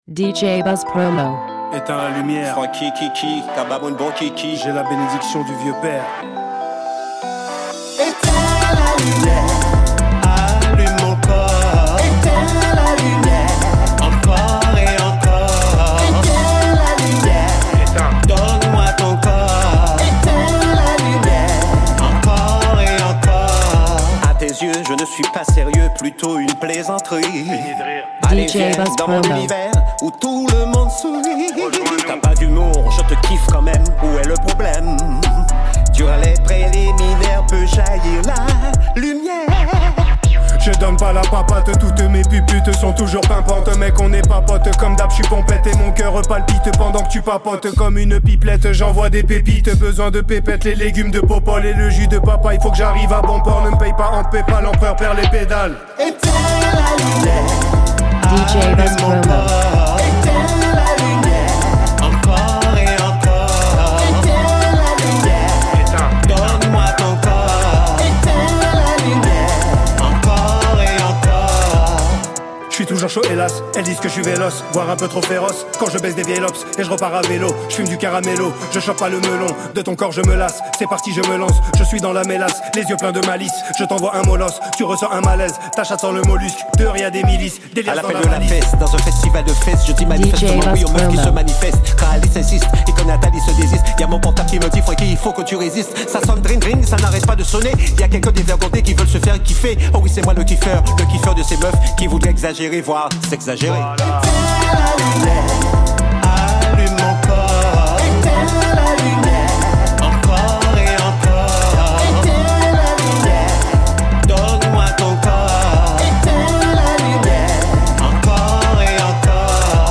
Original Mix